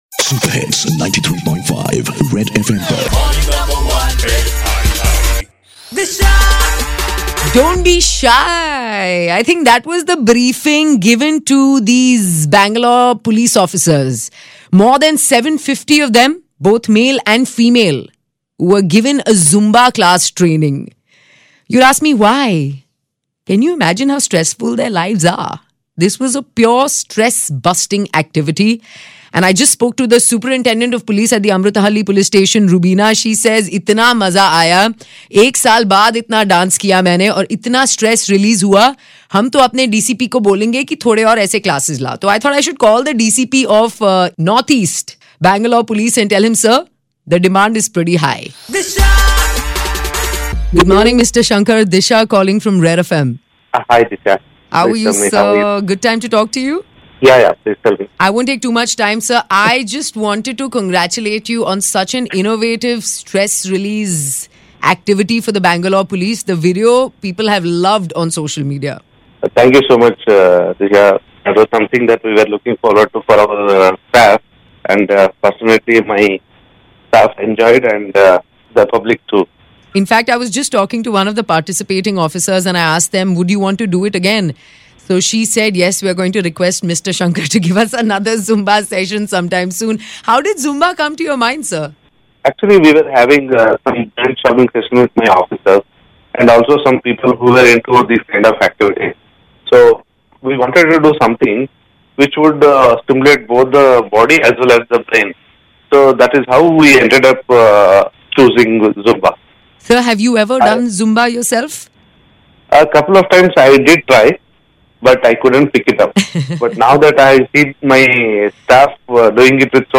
Dr Bheemashankar S Guled - DCP North East gives us an insight on 'Why Zumba'